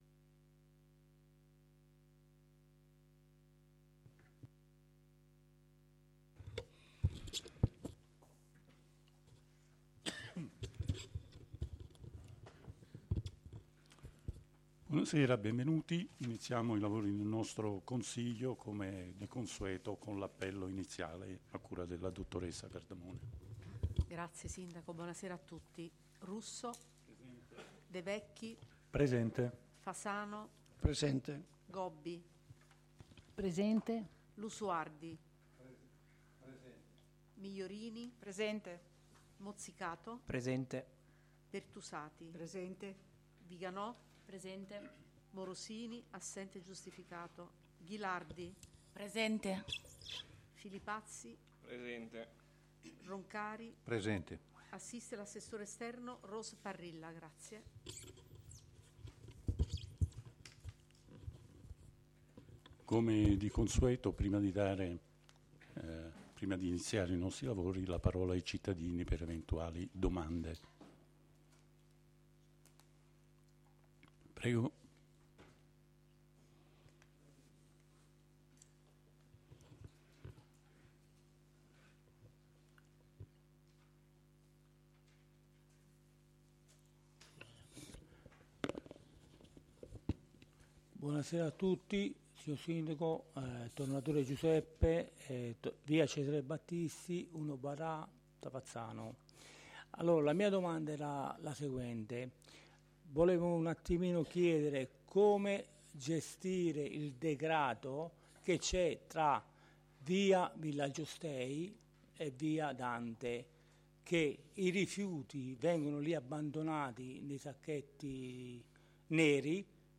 Seduta Consiglio Comunale 28 Novembre 2025